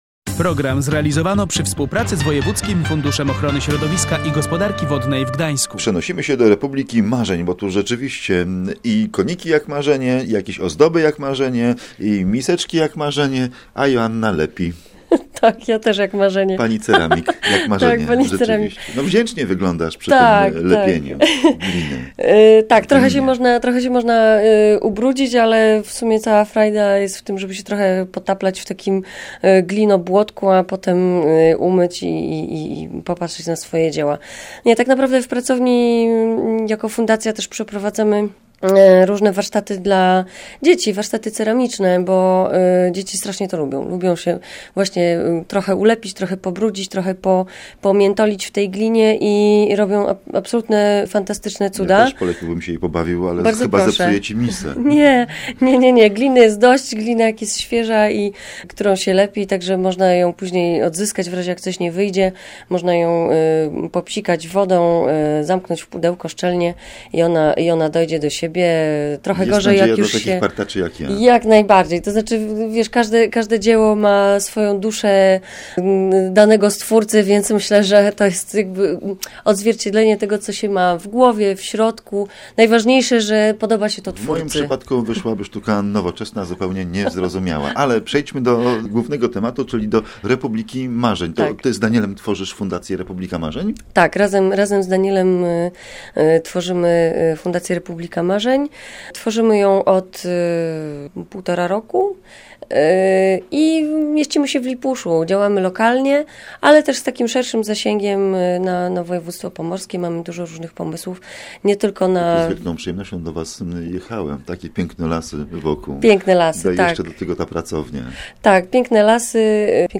Posłuchaj rozmowy /audio/dok1/eko-lipusz.mp3